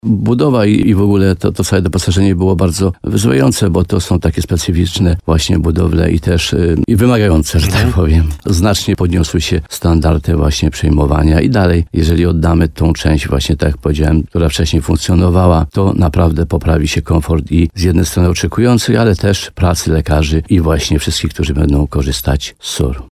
Jak przypomniał w programie Słowo za Słowo w radiu RDN Nowy Sącz starosta limanowski Mieczysław Uryga, pomimo skomplikowanej rozbudowy, SOR był wyłączony z działania tylko jedną dobę.